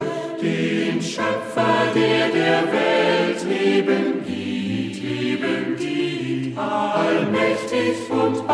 • Sachgebiet: Chormusik/Evangeliumslieder